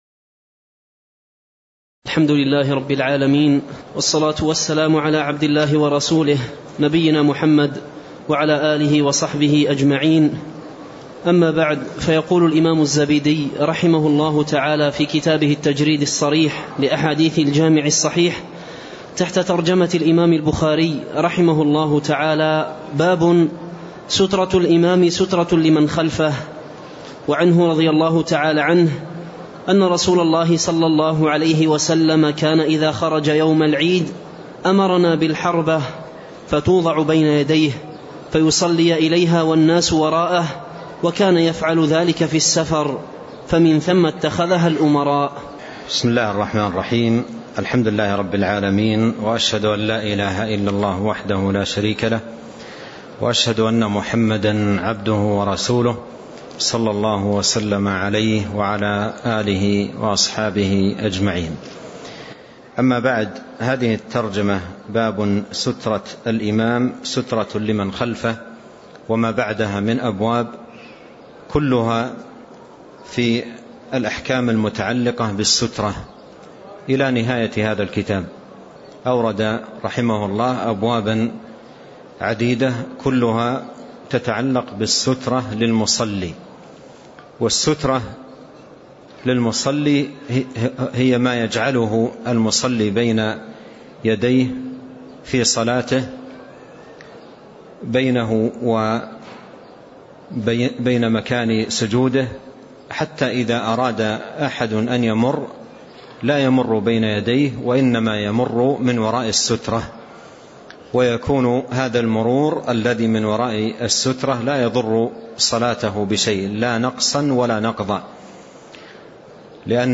تاريخ النشر ٢٤ جمادى الآخرة ١٤٣٣ هـ المكان: المسجد النبوي الشيخ